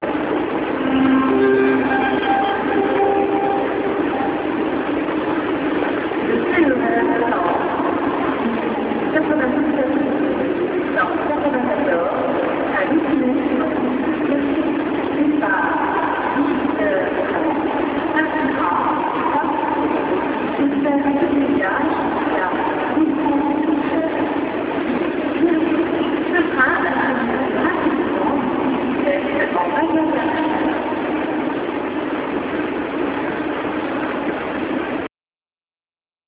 出発のアナウンス)